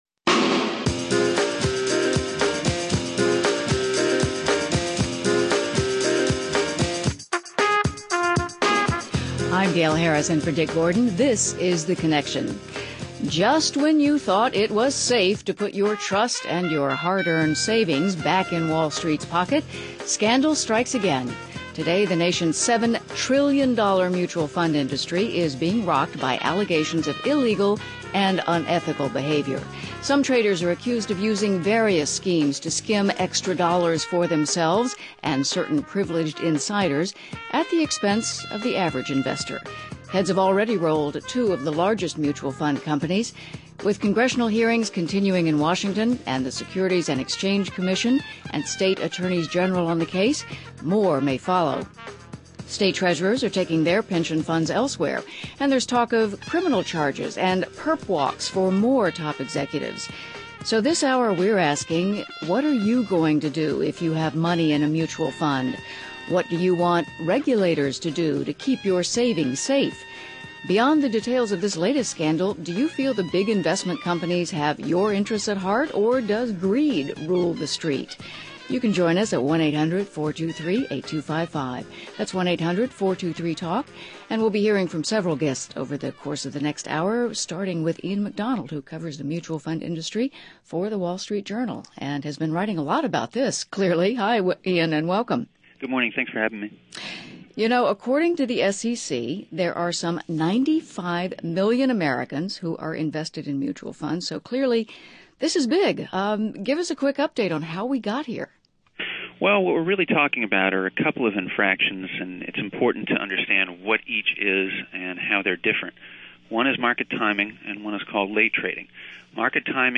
Guests: Paul Roye, Director, Division of Investment Management , U.S. Securities and Exchange Commission Gary Gensler, former Under Secretary for Domestic Finance, U.S. Treasury